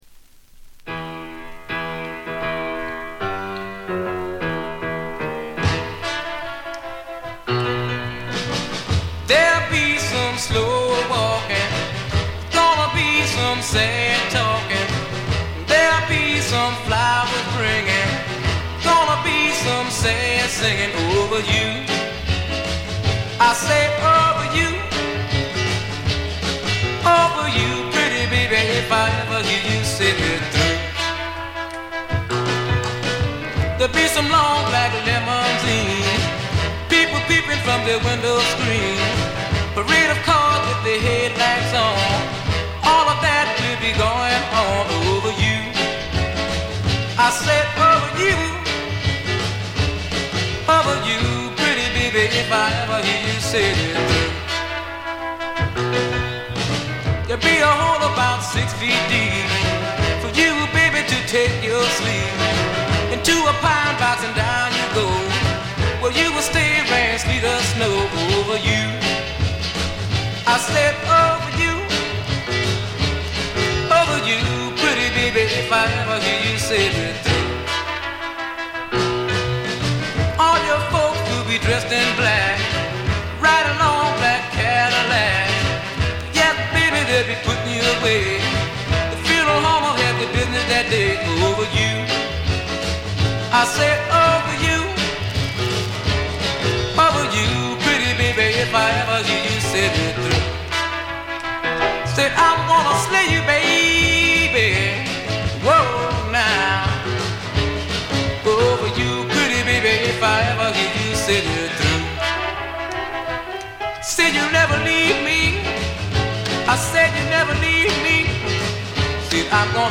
静音部で軽微なバックグラウンドノイズが聴かれる程度。
60年代初期のニューオーリンズ・サウンドがどんなものだったのか、ばっちりうかがうことができますね。
モノラル盤。
試聴曲は現品からの取り込み音源です。（STEREO針での録音です）